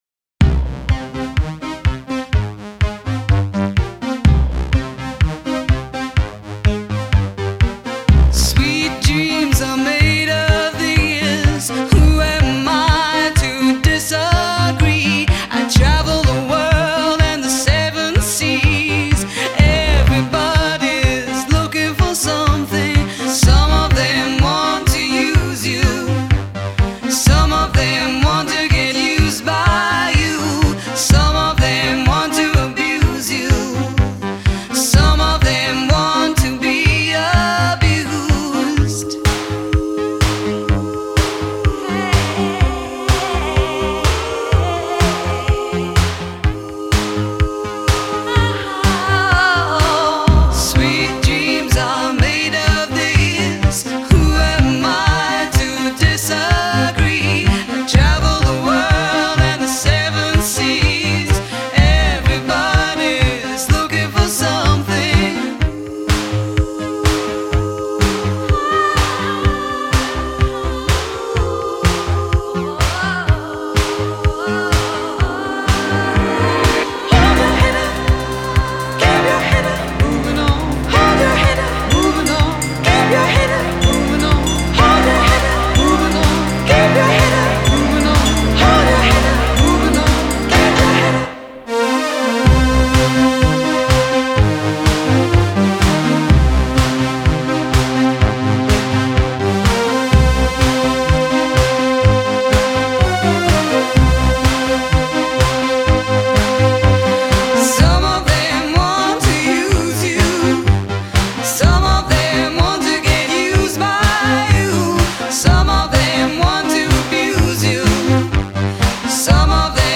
• Жанр: New Euro / Зарубежные песни